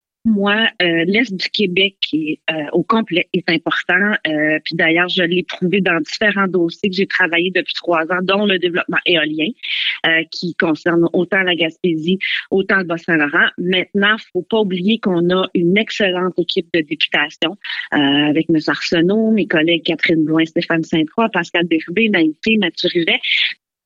En entrevue sur les ondes de Radio Gaspésie, Mme Dionne a mentionné qu’elle sera en mesure de bien faire son travail avec la députation en place :